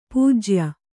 ♪ pūjya